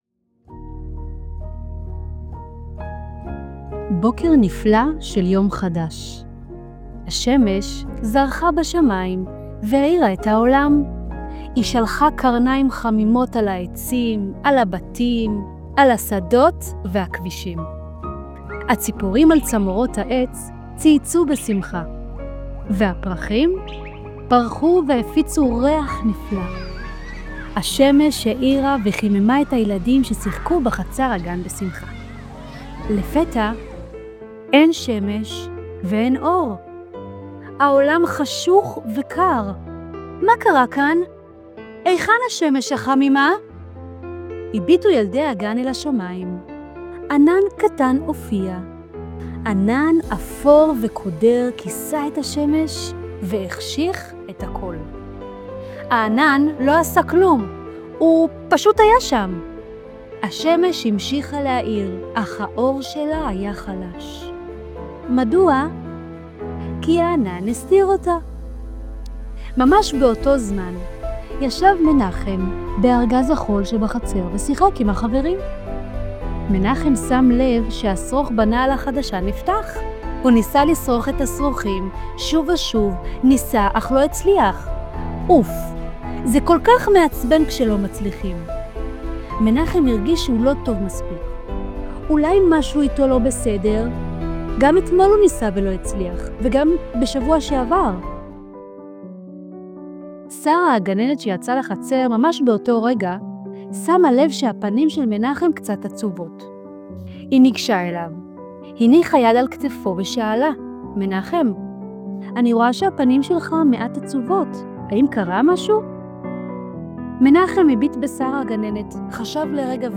שמש וענן – סיפור